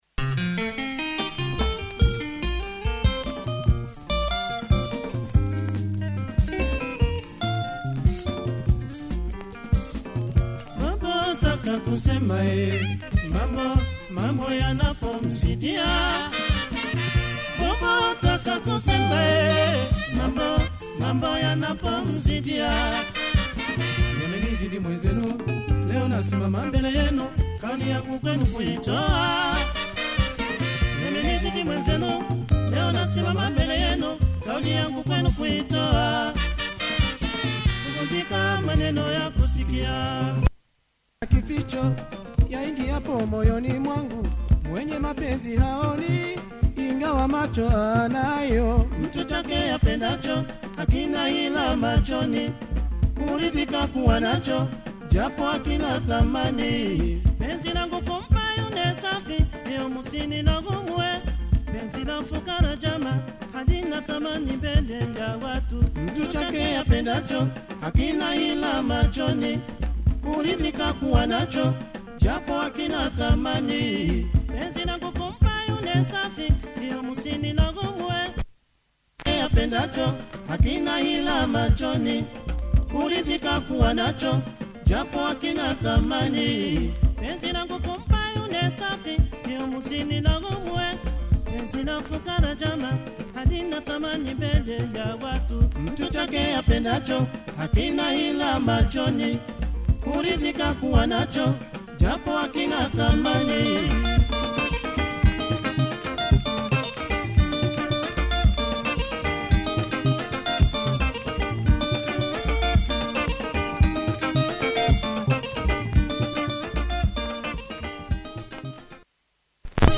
anyways good production, nice backbone changes pace mid-way